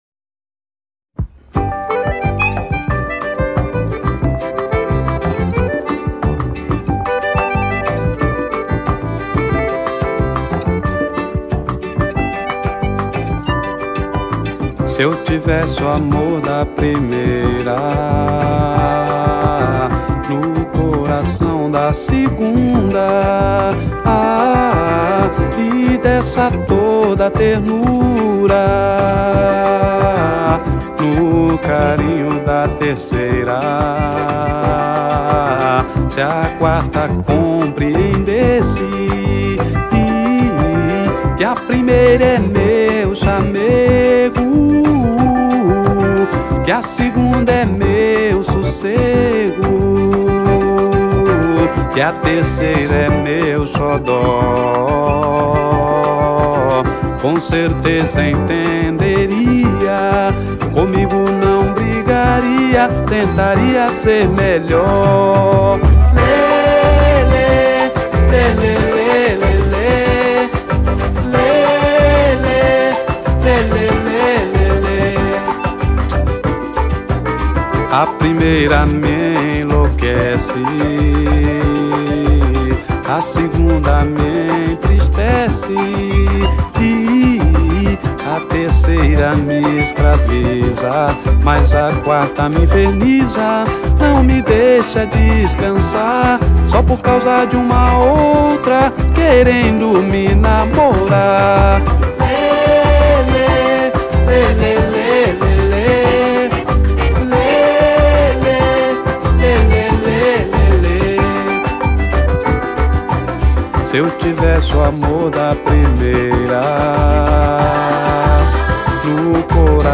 A Essência do Samba-Rock